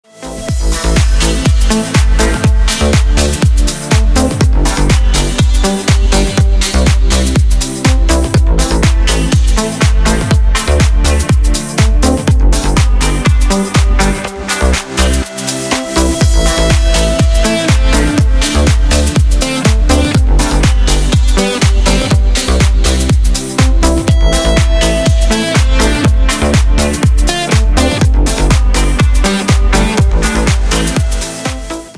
• Песня: Рингтон, нарезка
играет Deep House рингтоны🎙